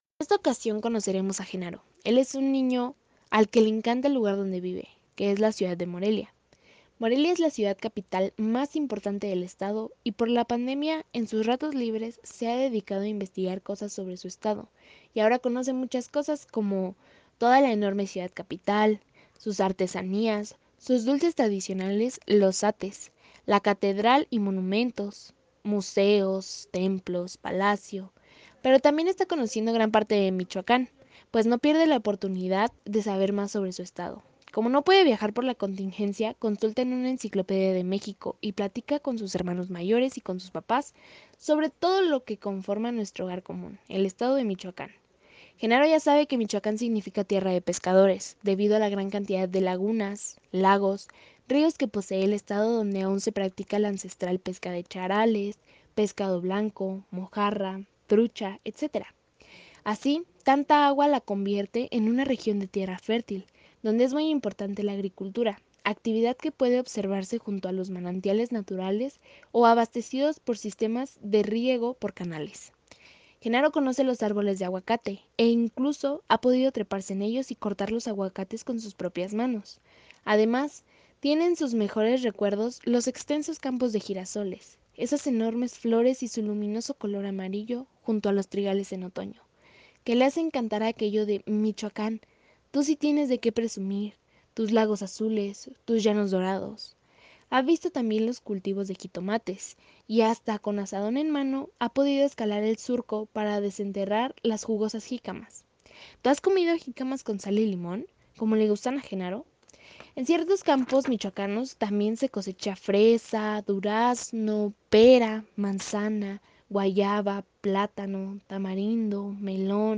CUENTO 1°